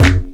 Kick 11.wav